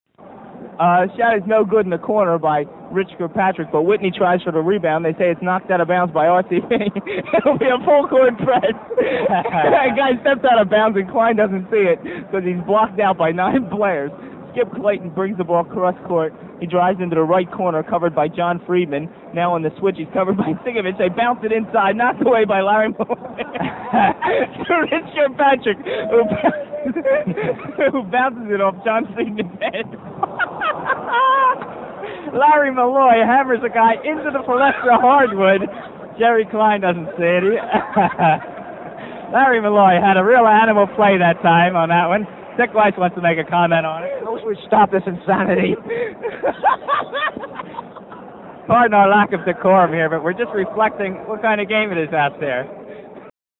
The game took place in Philadelphia's famous Palestra, at half-time of a real game between Temple and Penn State.
For that, we have the actual play-by-play audio.
Cut 6 (:54) is still more game action.